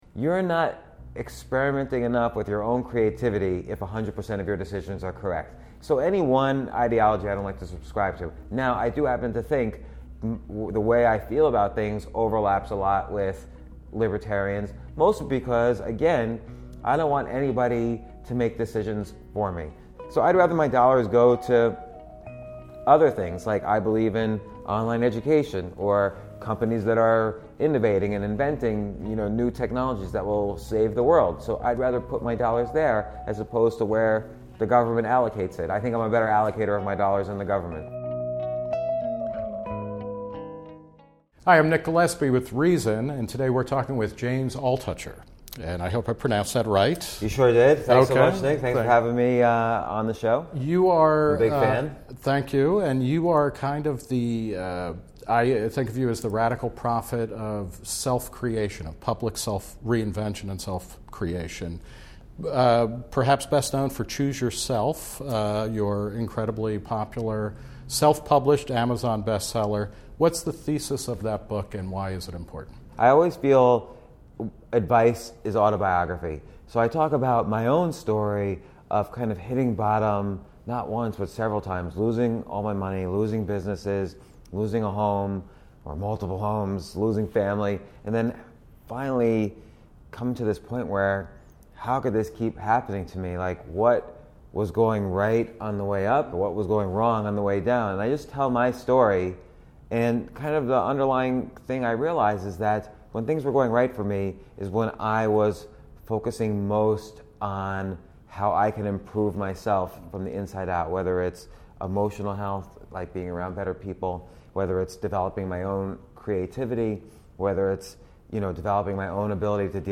Q&A with this riches-to-rags evangelist for personal reinvention.